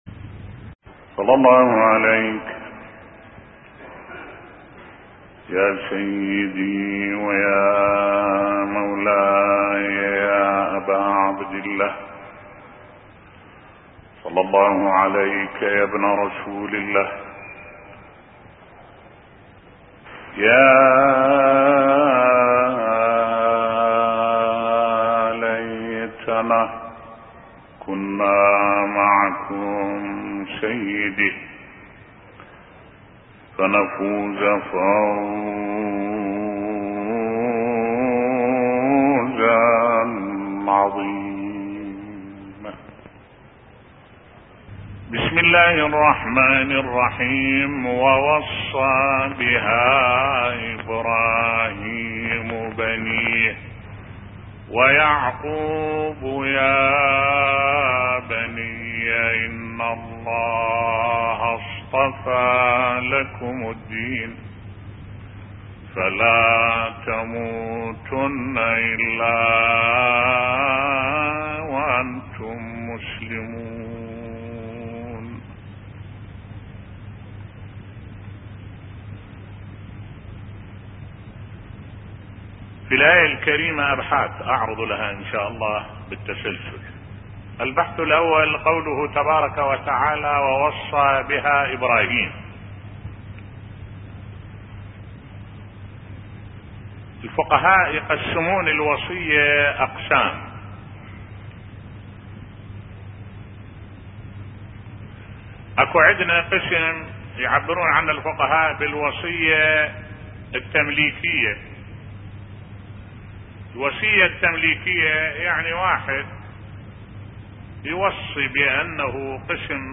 ملف صوتی ووصى بها ابراهيم بنيه ويعقوب يا بني بصوت الشيخ الدكتور أحمد الوائلي